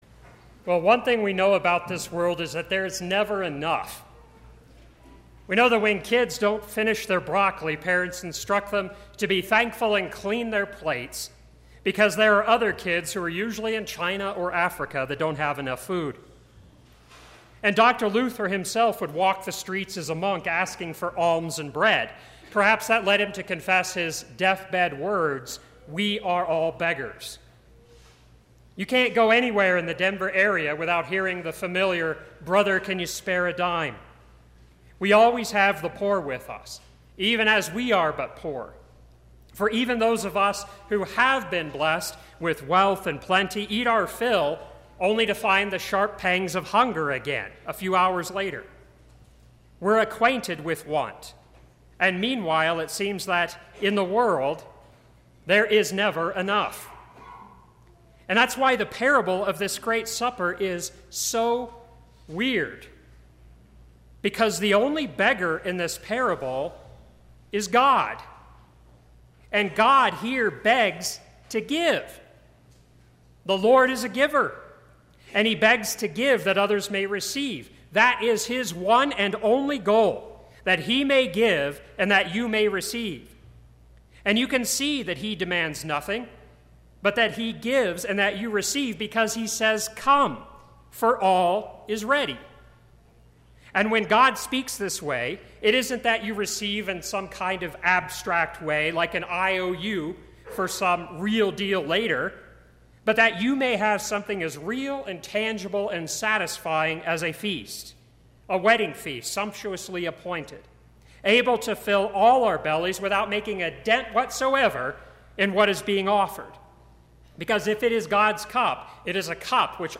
Second Sunday after Trinity
Sermon – 6/10/2018